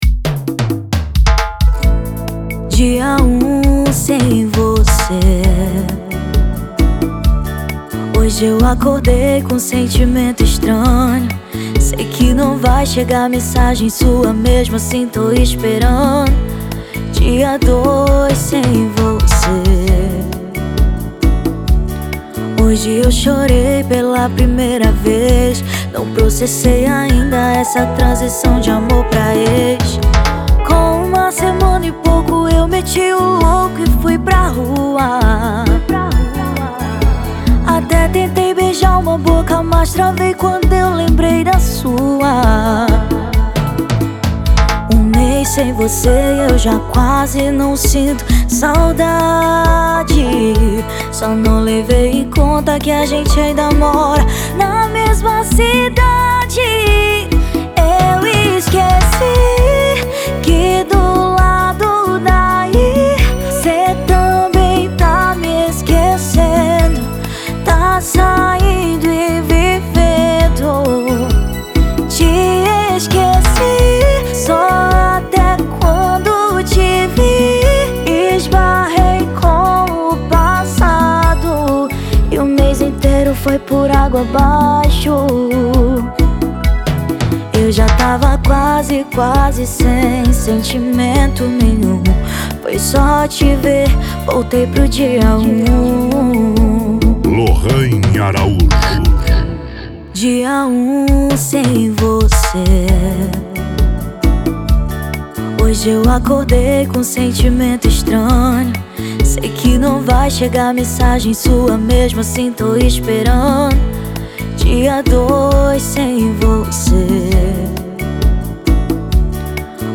OUÇA NO YOUTUBE Labels: Arrocha Facebook Twitter